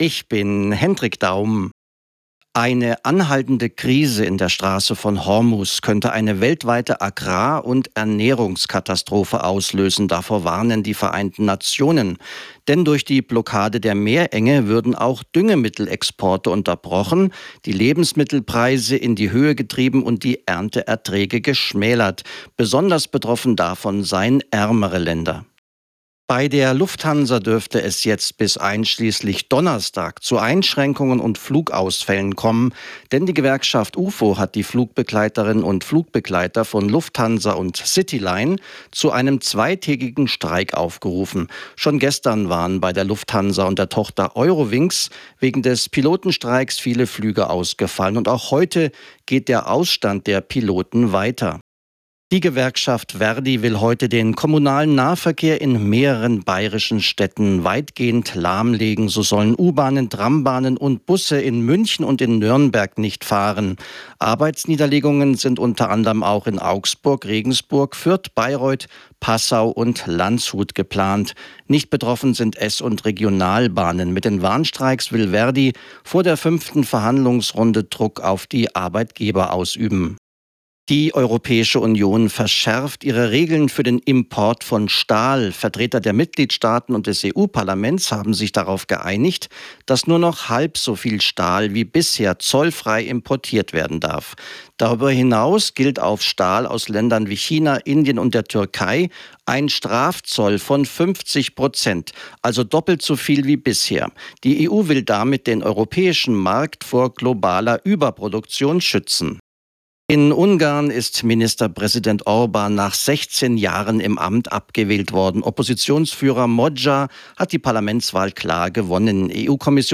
Nachrichten , Nachrichten & Politik